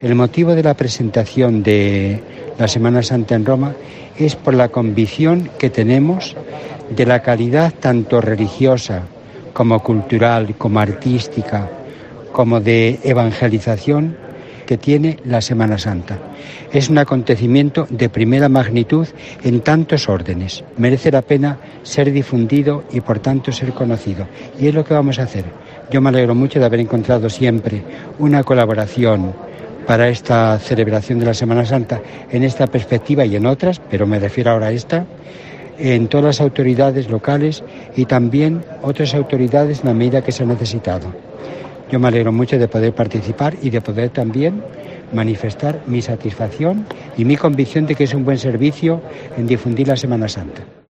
En declaraciones a COPE, el cardenal arzobispo de Valladolid ha manifestado su “convicción” de que “merece la pena difundir cómo celebramos la Semana Santa” por su “calidad, tanto religiosa como cultural”.